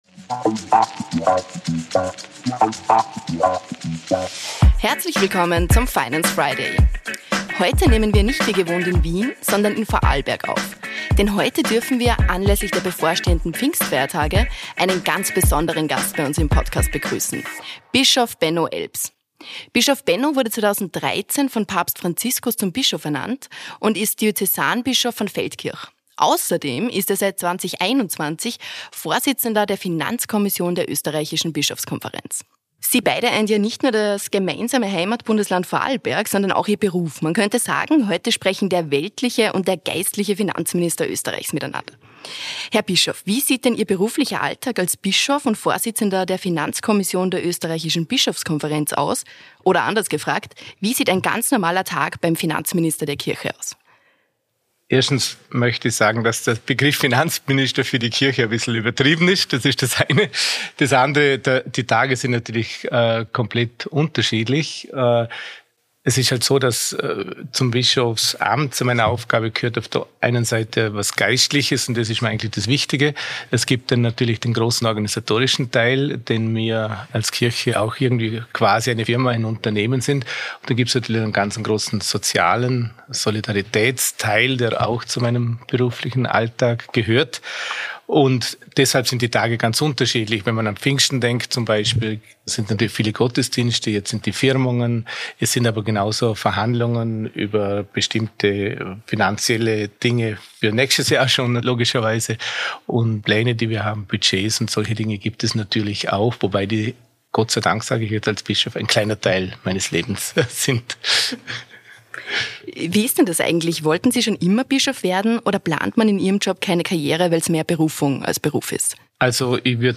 Beschreibung vor 1 Jahr In der aktuellen Folge des „Finance Friday” spricht Finanzminister Magnus Brunner mit Bischof Benno Elbs, der nicht nur Bischof, sondern seit 2021 auch Vorsitzender der Finanzkommission der österreichischen Bischofskonferenz ist. Die beiden Herren eint nicht nur ihre Heimat Vorarlberg, sondern auch ihr Beruf, denn beide beschäftigen sich mit Finanzen - man könnte sagen, in dieser Folge sprechen der weltliche und der geistliche Finanzminister Österreichs miteinander.